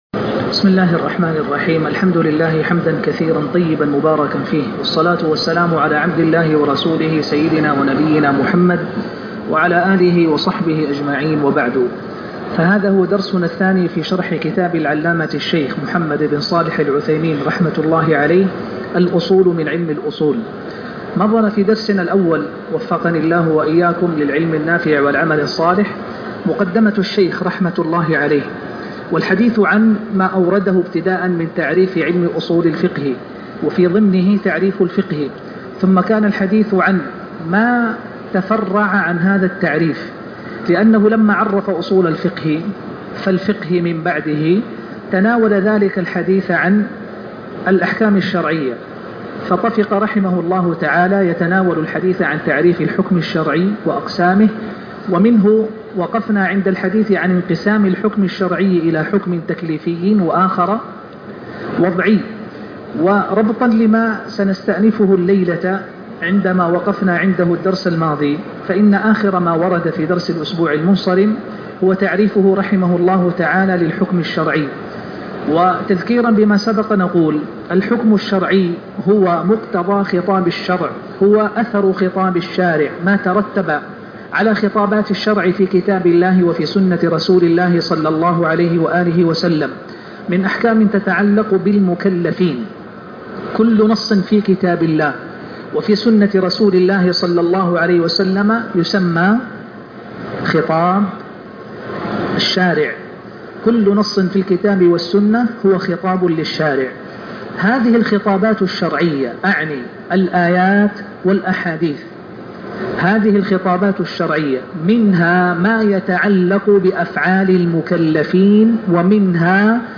الدرس الثاني من شرح (الاصول من الاصول لابن عثيمين )